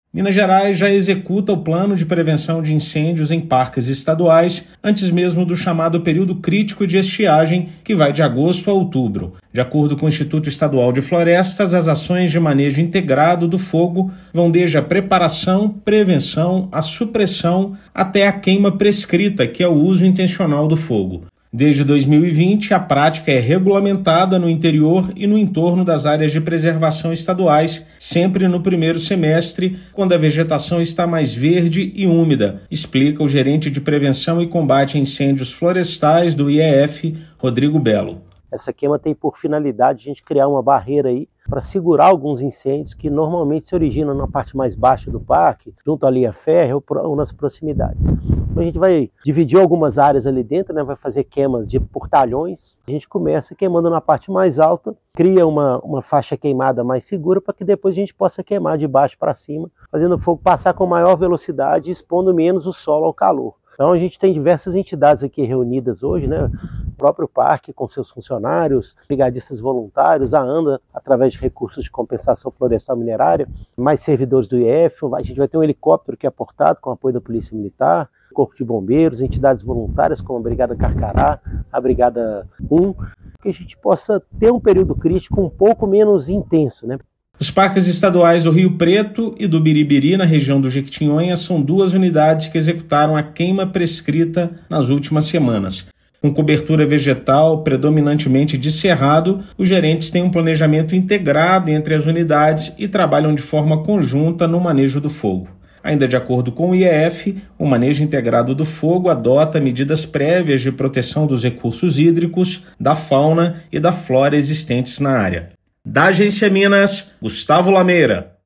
[RÁDIO] Minas adota prevenção a incêndios florestais como prioridade e se antecipa ao período seco